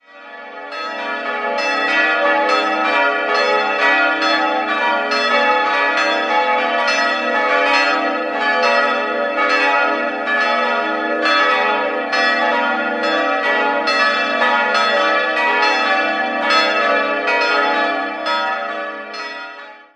4-stimmiges Gloria-TeDeum-Geläute: a'-h'-d''-e''
Glocke 1 a'-7
Glocke 4 e''-4 193 kg 658 mm 2010 Albert Bachert, Heilbronn
Heutiges Geläut (seit 2010)